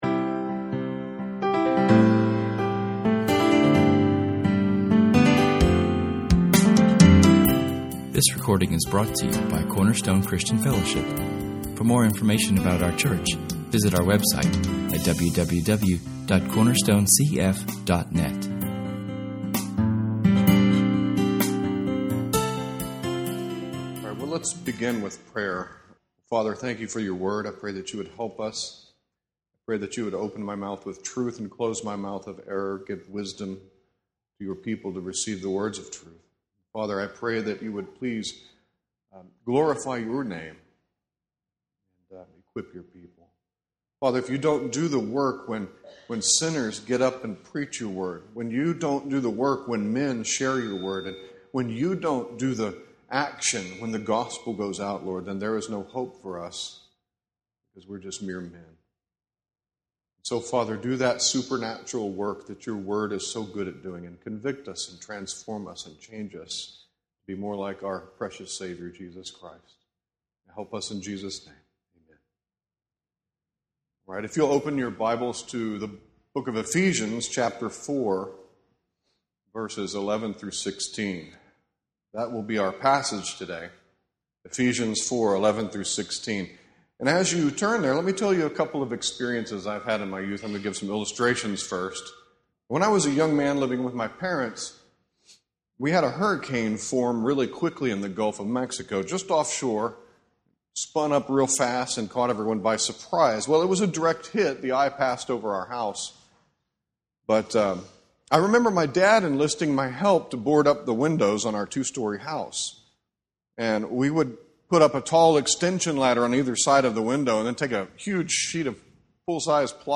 Our sermon, from [esvignore]Ephesians 4:11-16[/esvignore] examines the work and ministry of God’s gifts to His people for the purposes of equipping the church. Here we find the idea of maturity and growth by means of speaking the truth in love.